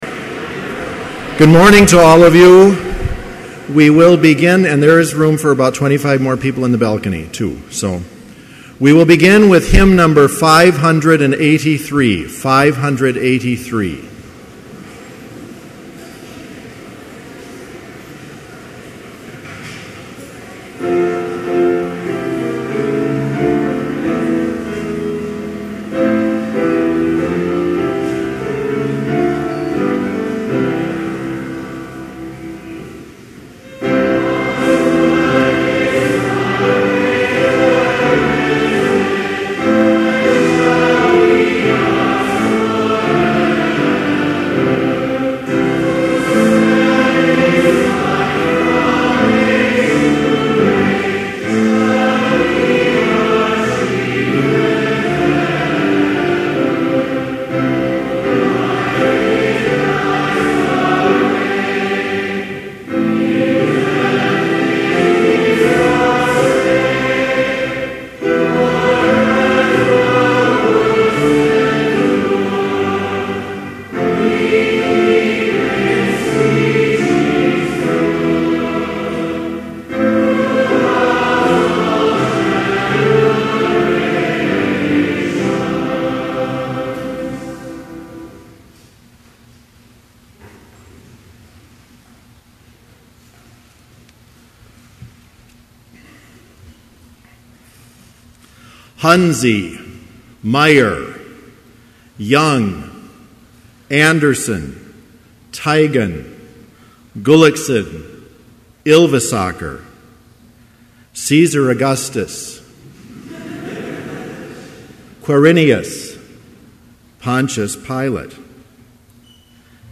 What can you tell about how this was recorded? Complete service audio for Chapel - August 24, 2011